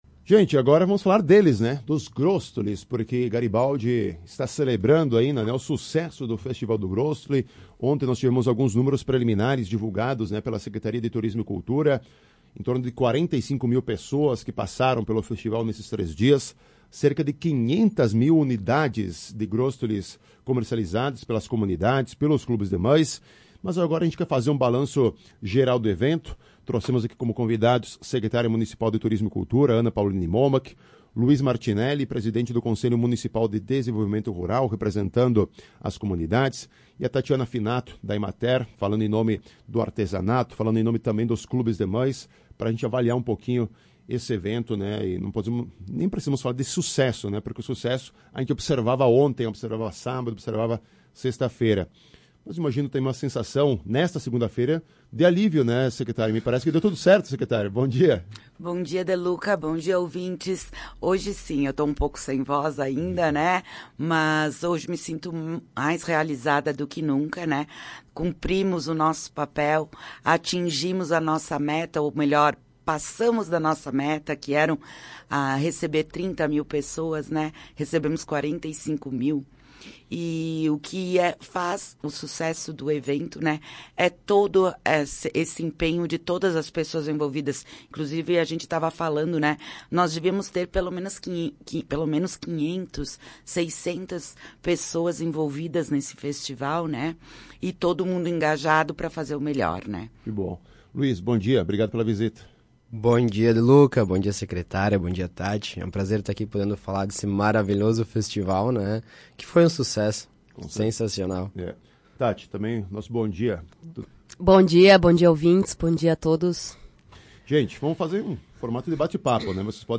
Participaram de entrevista no Comando Geral